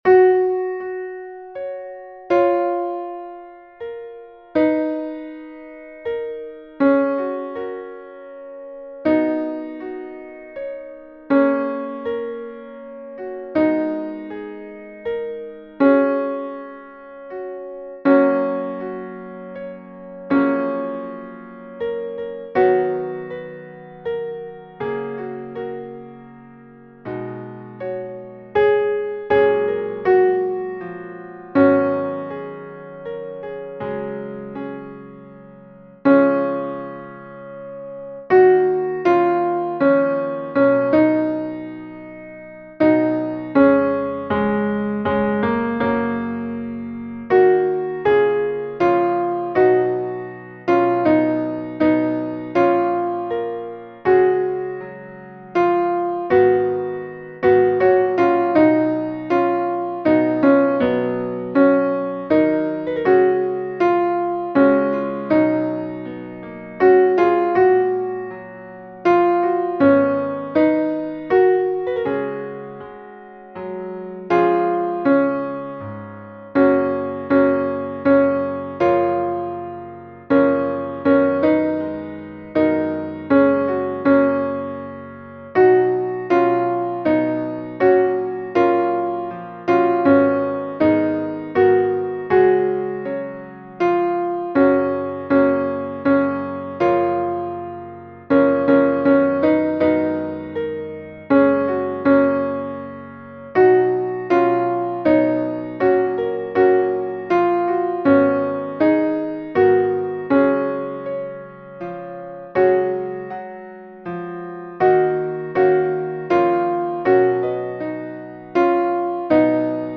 Versions piano